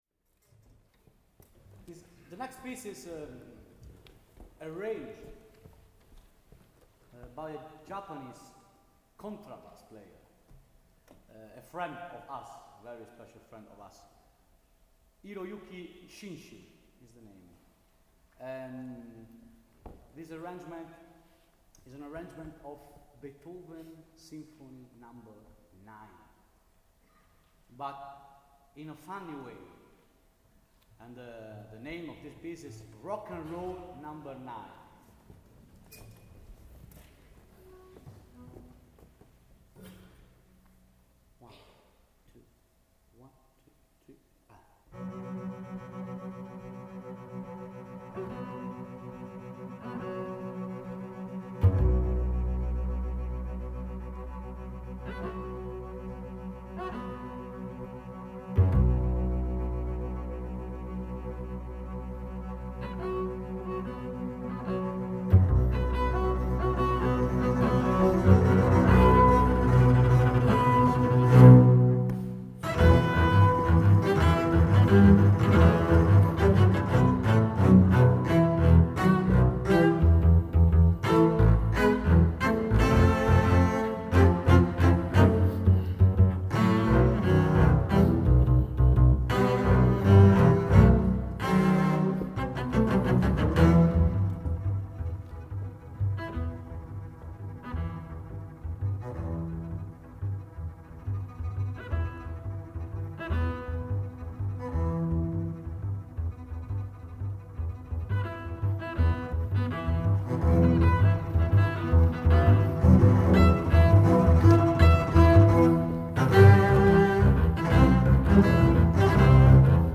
funny arragement
Live Recordings from the Concert at
WATANABE MEMORIAL HALL